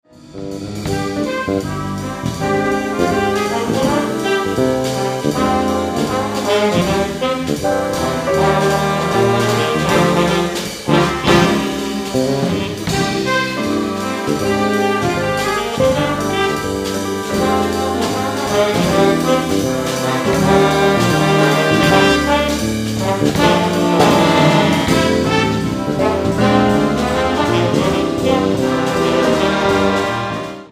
Back to normal big band line-up.
A Sax feature with solos throughout the section.
Line-up: 2 Altos, 2 Tenors, Baritone.